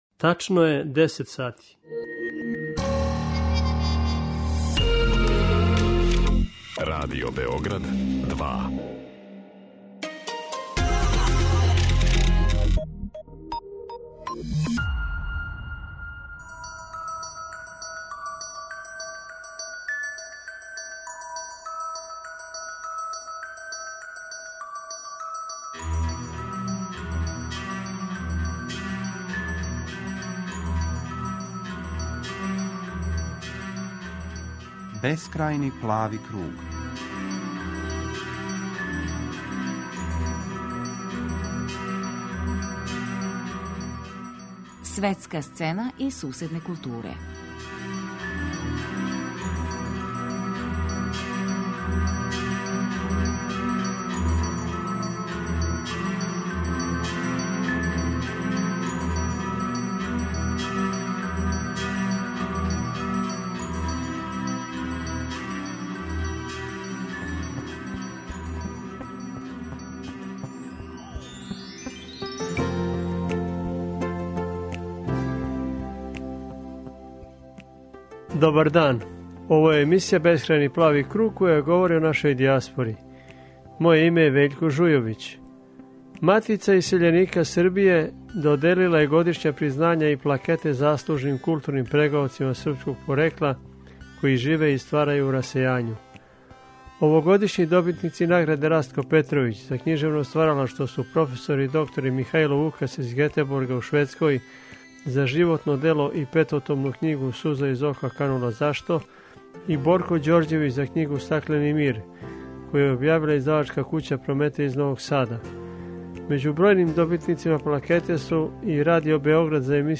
Данас ћемо чути разговоре